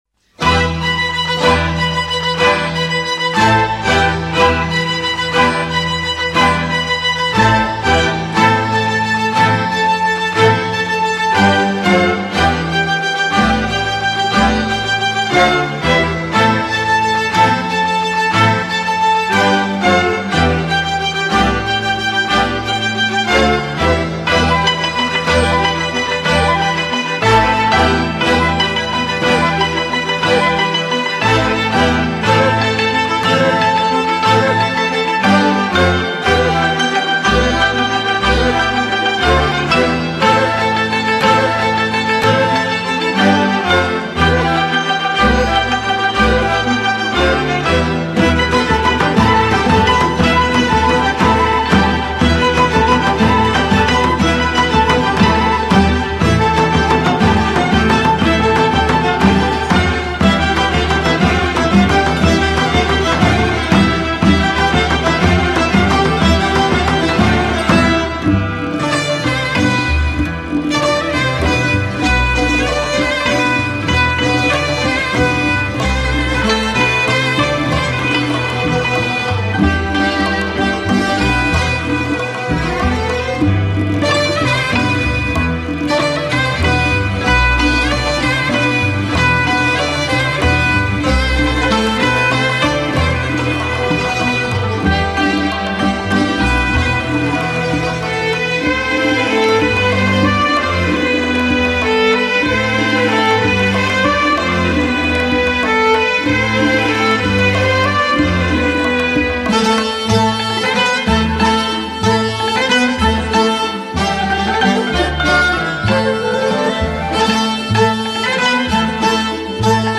اهنگ شمالی قدیمی